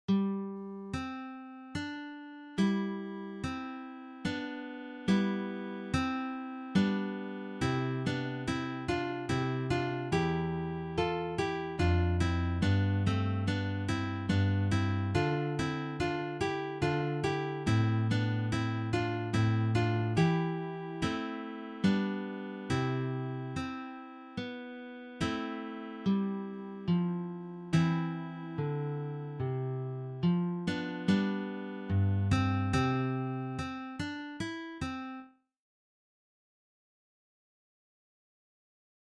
for three guitars
This is from the Baroque period.